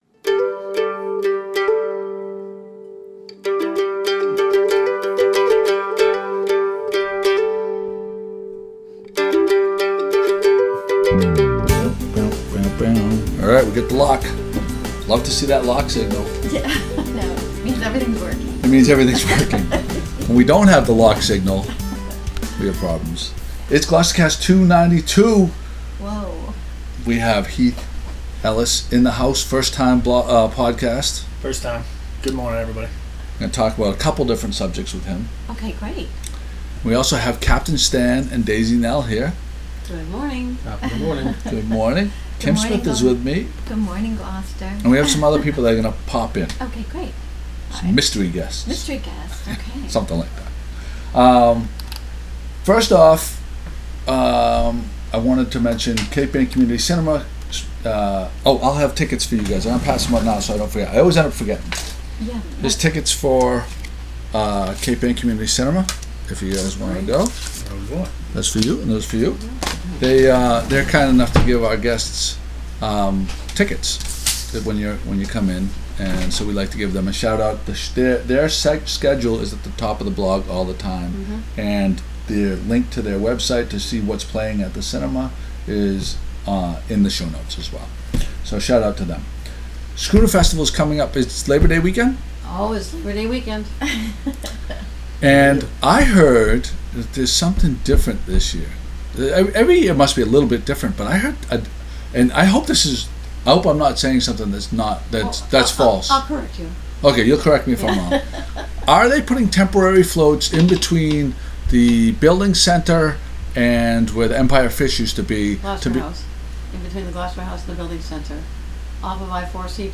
I am horribly horribly sorry that the last 50 minutes of teh podcast is lost with me having no way to retrieve it.